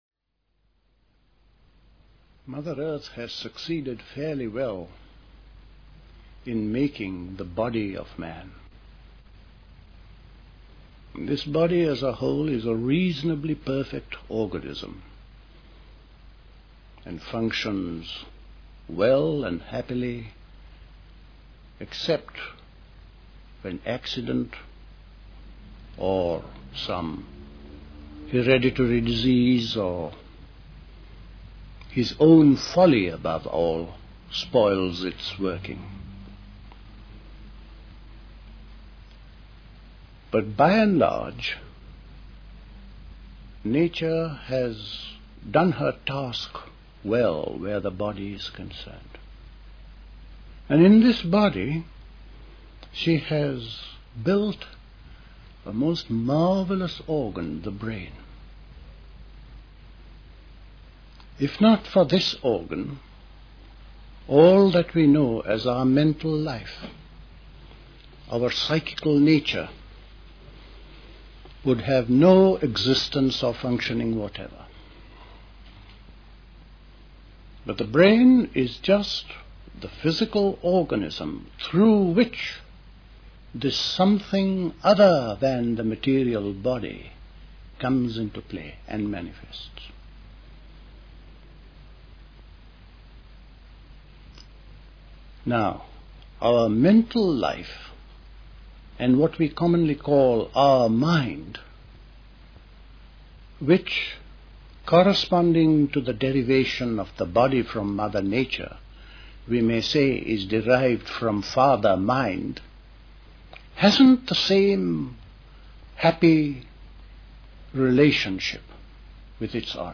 A talk
at Dilkusha, Forest Hill, London on 8th November 1970